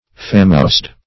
famoused - definition of famoused - synonyms, pronunciation, spelling from Free Dictionary Search Result for " famoused" : The Collaborative International Dictionary of English v.0.48: Famoused \Fa"moused\, a. Renowned.
famoused.mp3